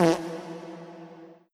revfart.wav